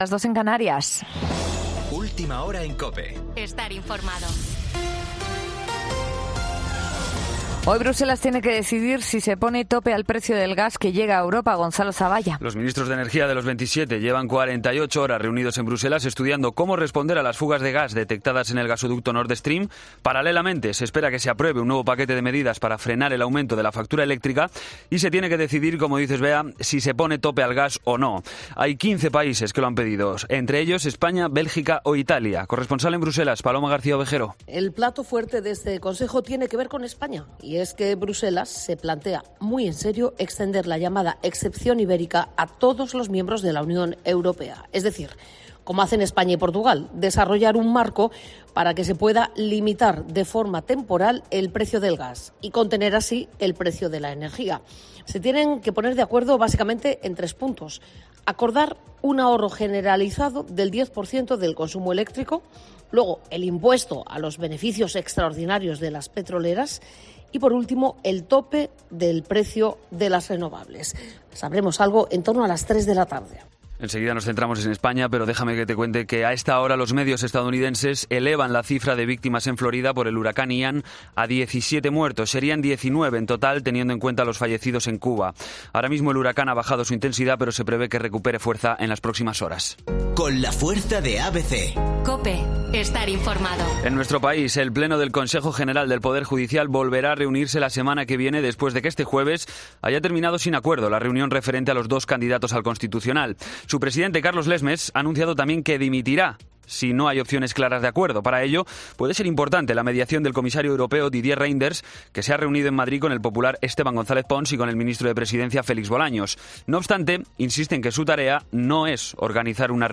Boletín de noticias COPE del 30 de septiembre a las 03:00 hora
AUDIO: Actualización de noticias Herrera en COPE